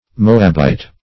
Moabite \Mo"ab*ite\, n.